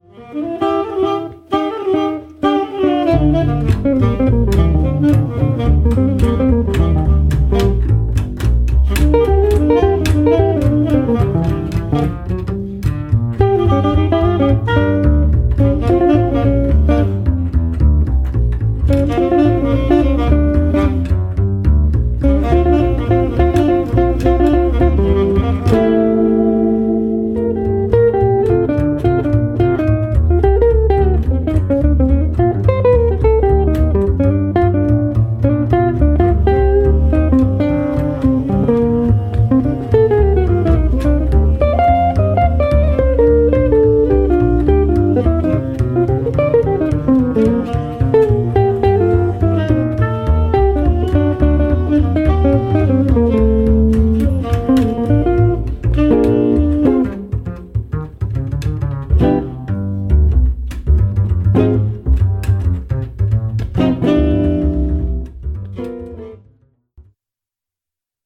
saxophone ténor, clarinette
guitare
contrebasse
batterie